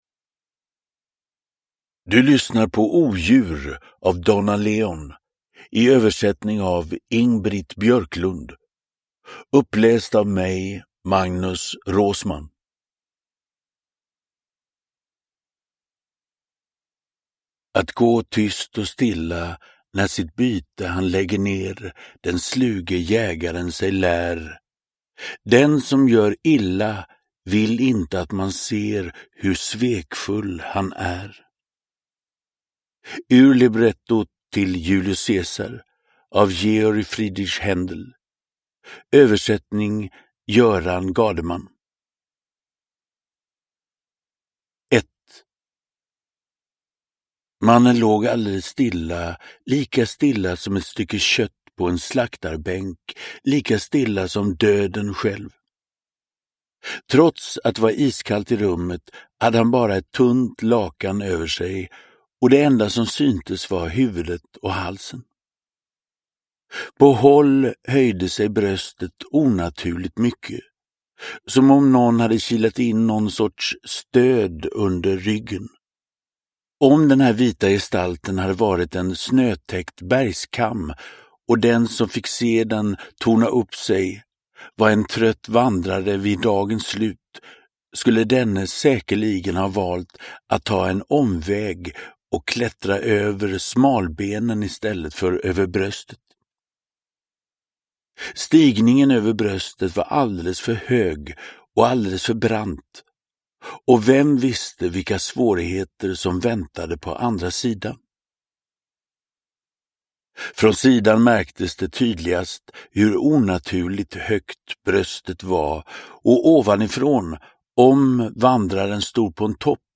Odjur – Ljudbok – Laddas ner
Uppläsare: Magnus Roosmann